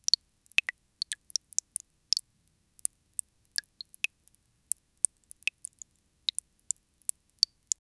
Sticky drips, playful and soft.
sticky-drips-playful-and--emxjq724.wav